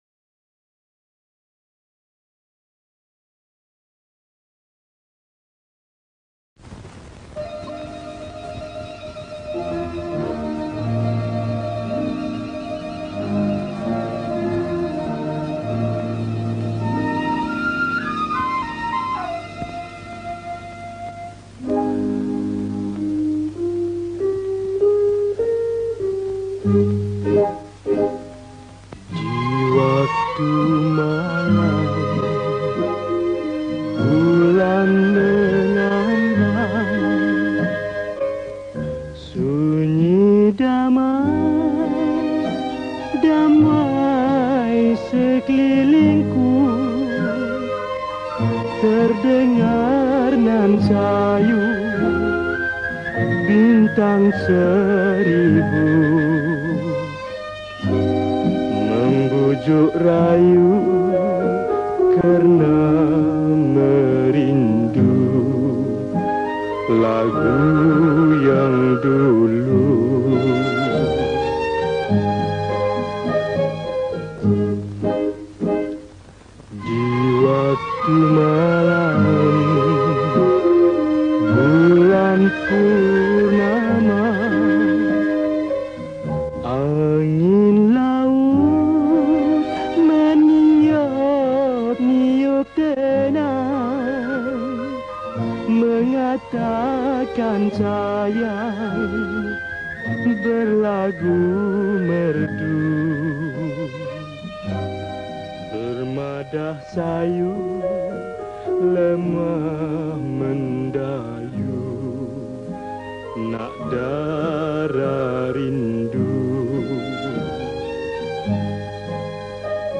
Malay Songs
Solo Recorder